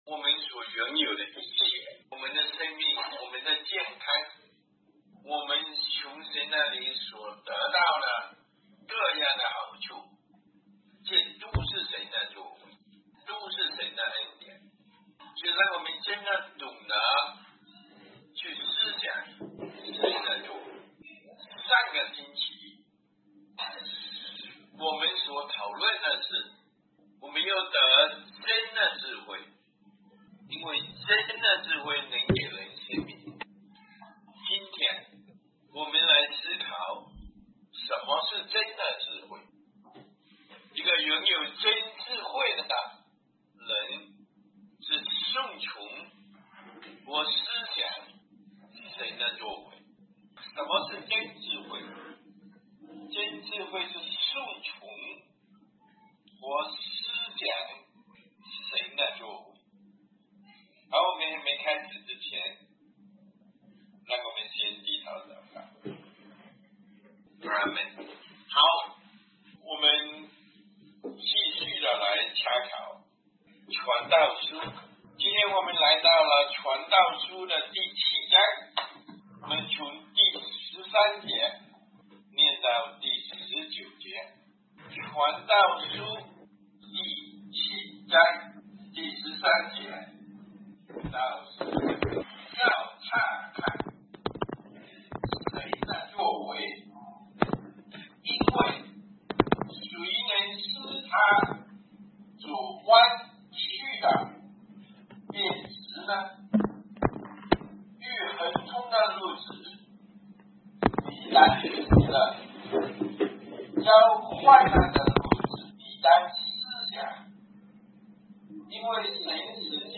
最新讲道录音